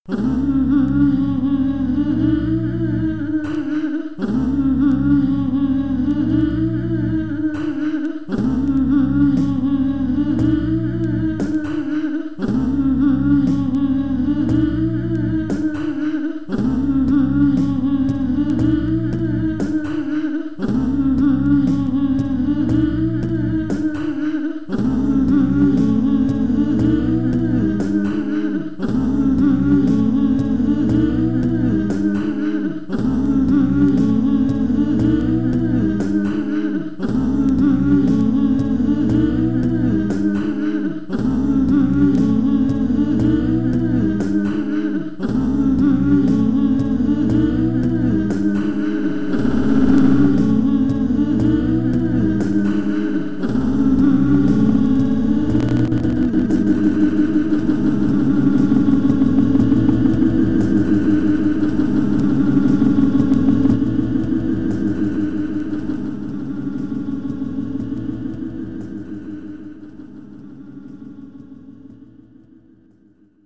Форум российского битбокс портала » Реорганизация форума - РЕСТАВРАЦИЯ » Выкладываем видео / аудио с битбоксом » Баловство (Трек в Amiloop)
норм, но хэты отработай